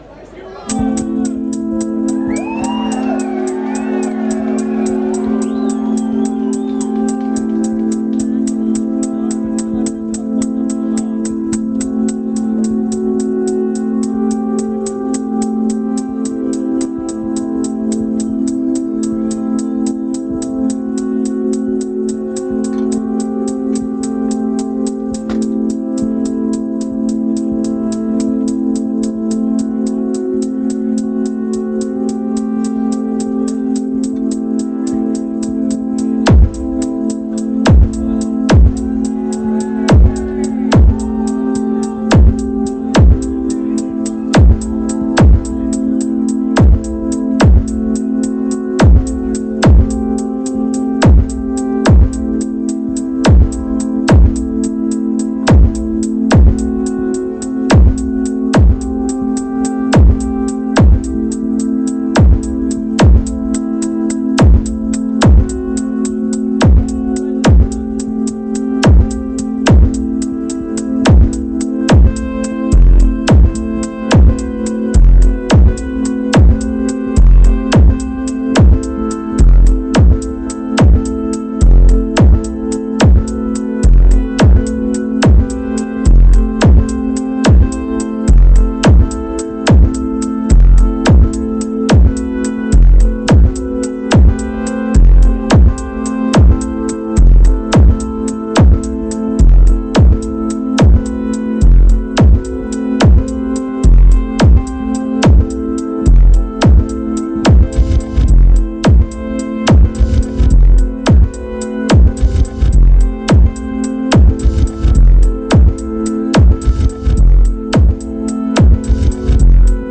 location Sussex, UK venue Camber Sands